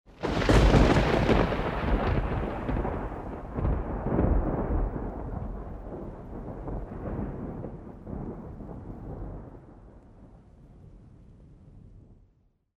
Гроза подальше с затихающими грохотаниями — 12 сек